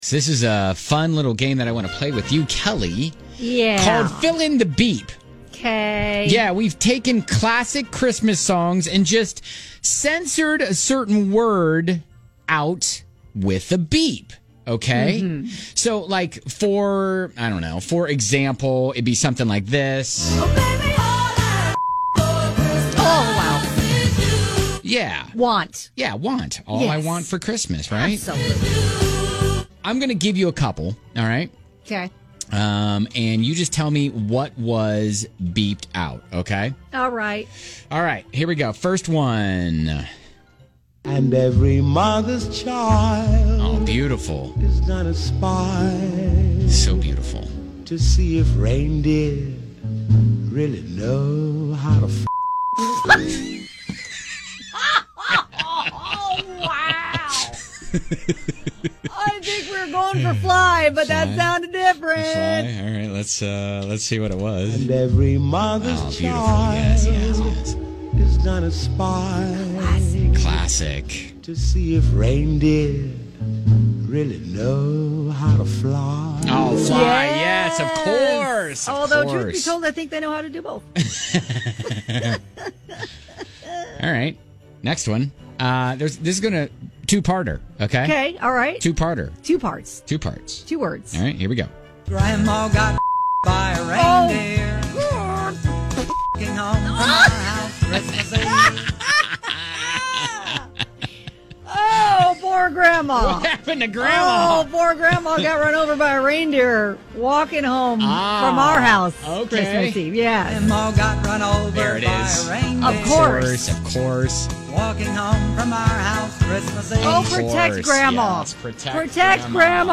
We’ve taken classic Christmas songs and censored certain words with a beep - just fill in the BEEP and win!